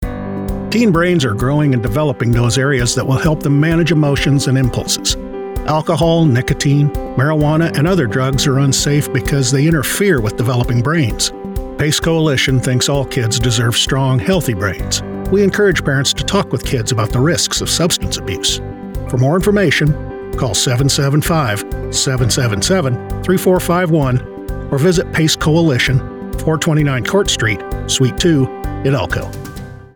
Male
A Recent Psa
Words that describe my voice are Storyteller, Conversational, Relatable.
0130Pace_Coalition_radio_spot.mp3